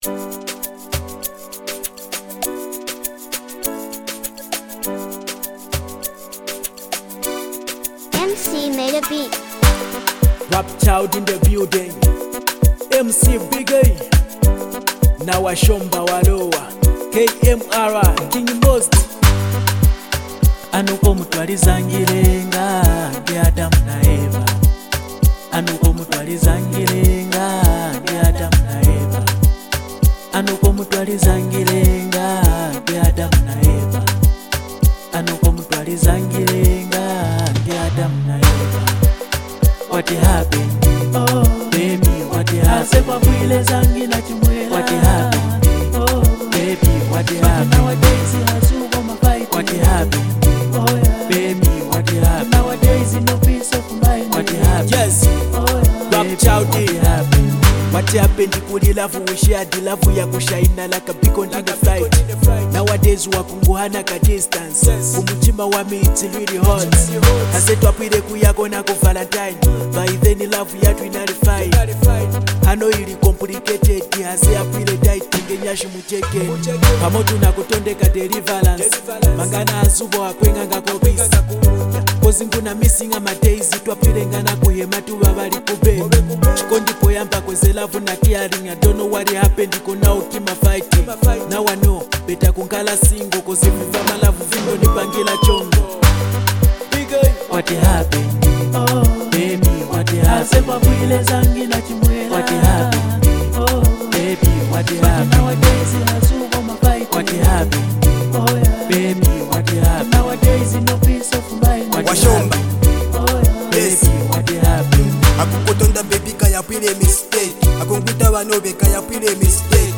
soulful R&B record